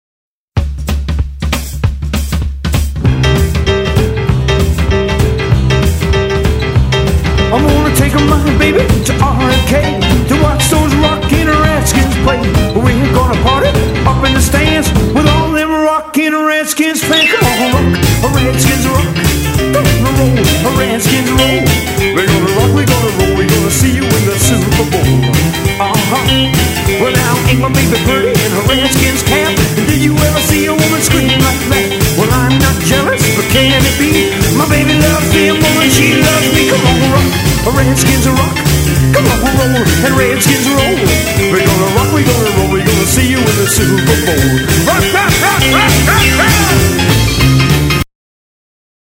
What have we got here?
Genre: Rockabilly